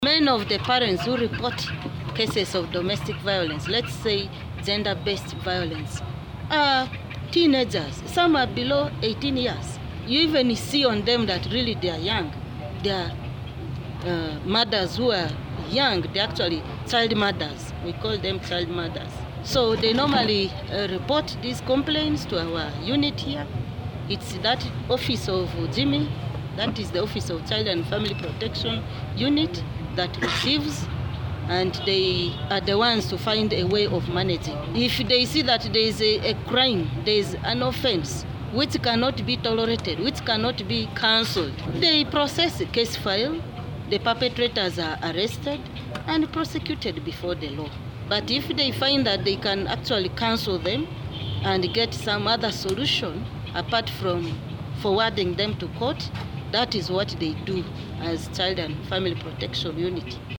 Police in an engagement with journalists of west Nile organized by MEMPROW